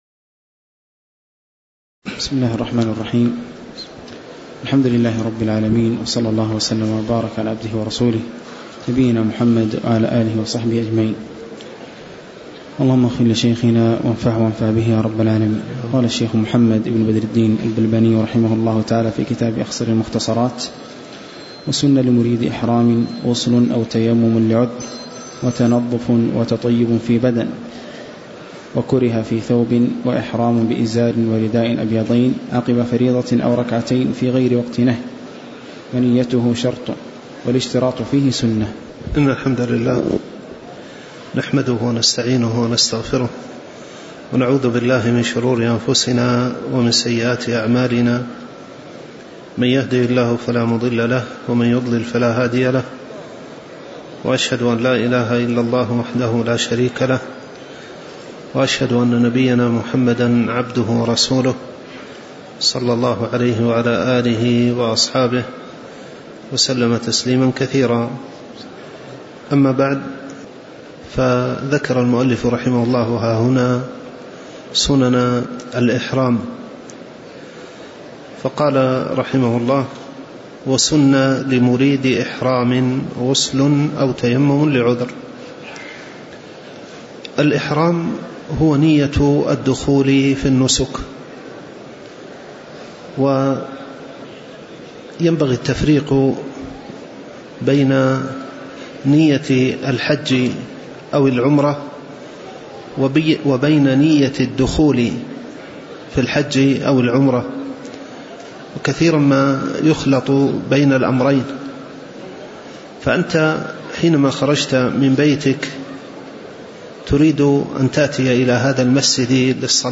تاريخ النشر ٨ صفر ١٤٤٠ هـ المكان: المسجد النبوي الشيخ